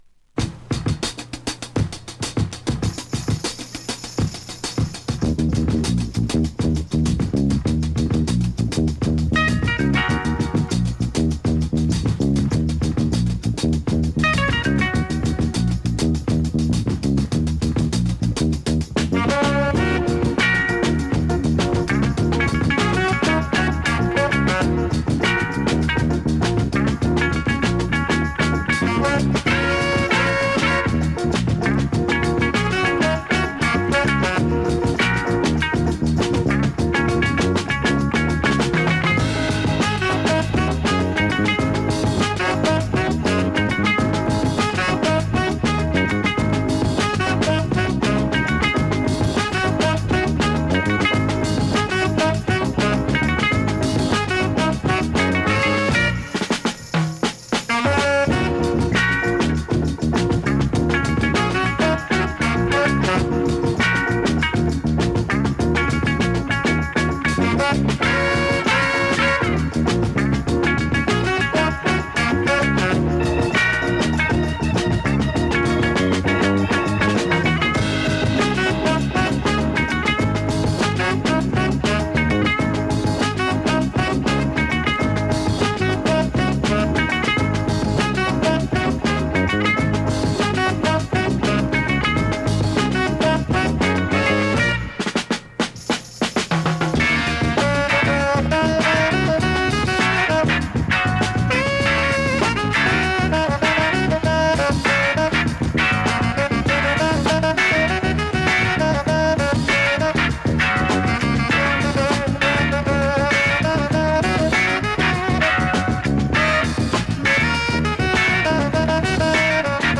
現物の試聴（両面すべて録音時間８分）できます。